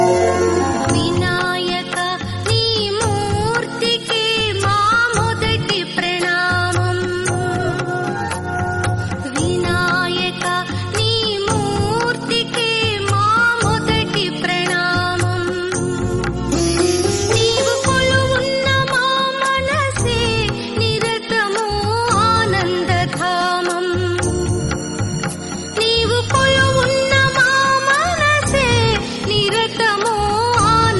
best flute ringtone download
bhajan ringtone download